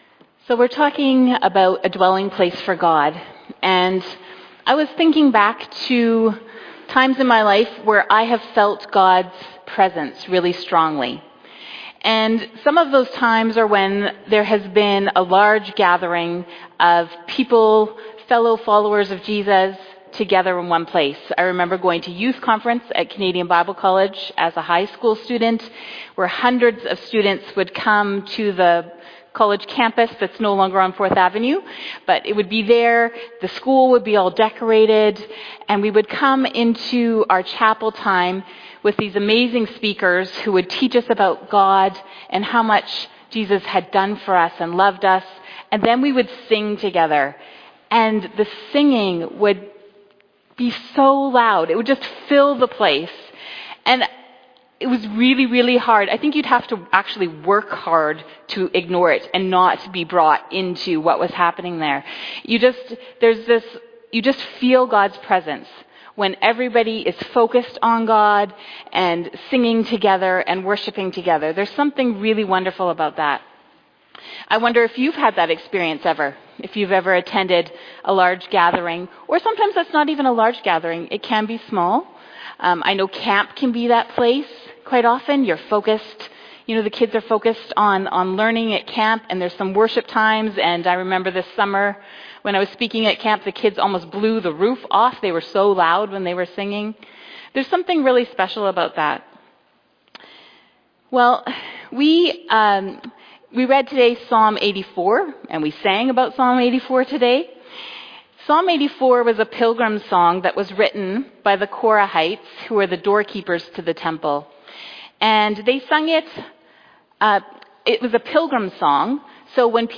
fbcsermon_2024_Sept1.mp3